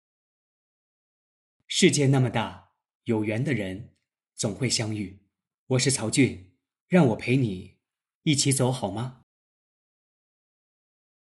Reflekterande Berättande AI-röst
Text-till-tal
Introspektiv Ton
Filmiskt Tempo
Memoarberättande
Dokumentärröst